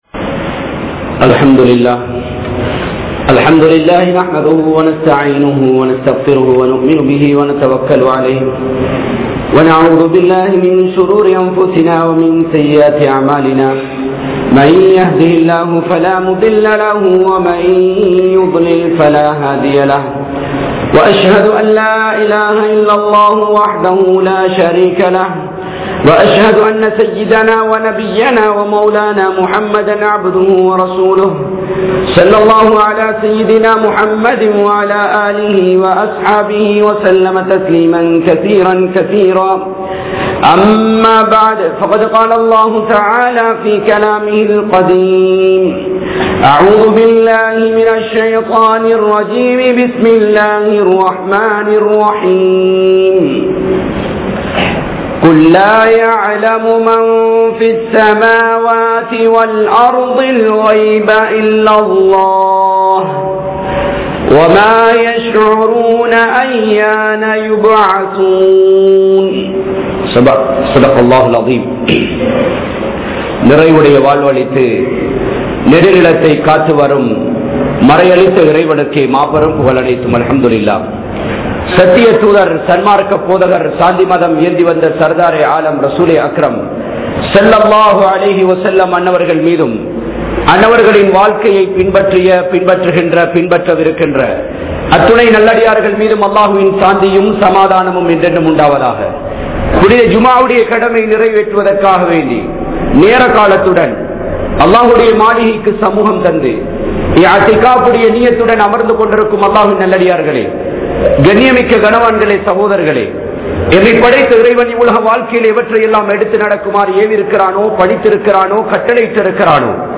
Qiyaamath Naalin Adaiyaalangal(கியாமத் நாளின் அடையாளங்கள்) | Audio Bayans | All Ceylon Muslim Youth Community | Addalaichenai
Badhriyeen Jumua Masjith